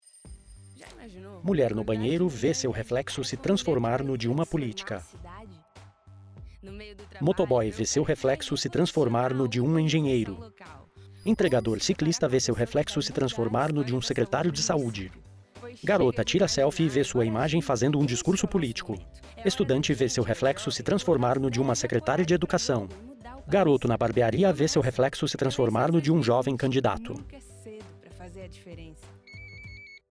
AUDIO_DESCRICAO_TSE_CANDIDATE_SE_JOVEM_AD.mp3